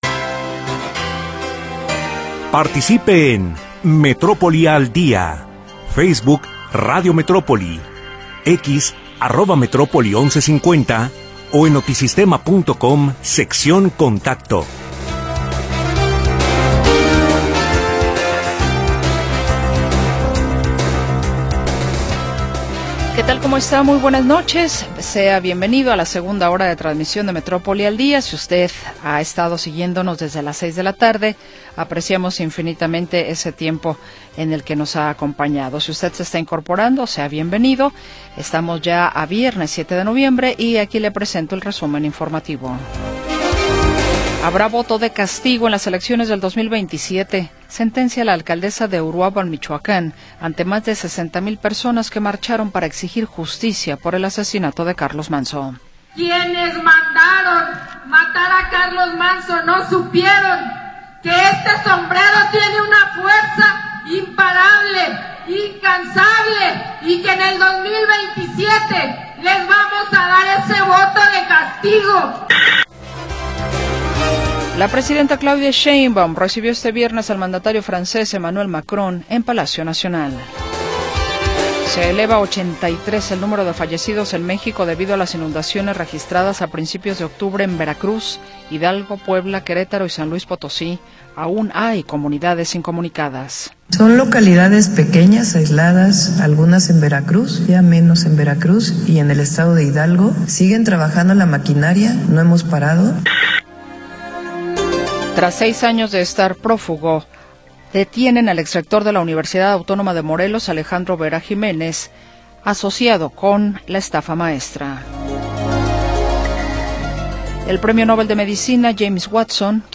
Segunda hora del programa transmitido el 7 de Noviembre de 2025.